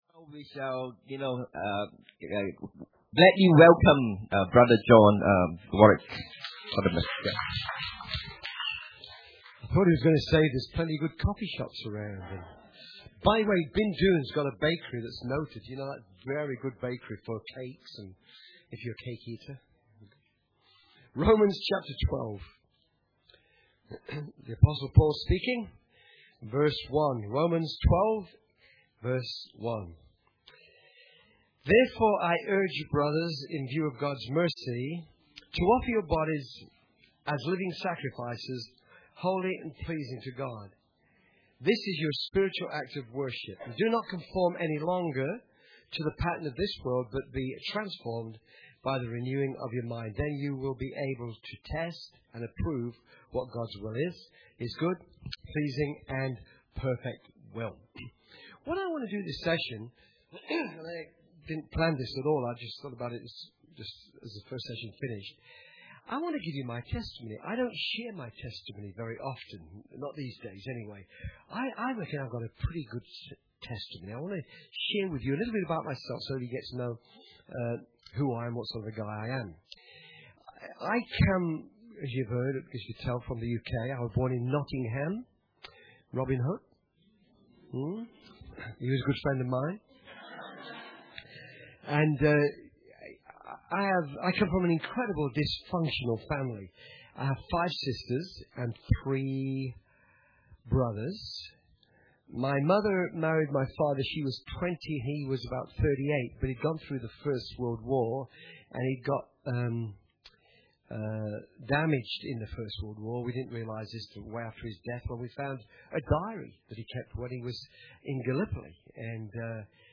Church Camp 2014 – Friday_Morn_Session2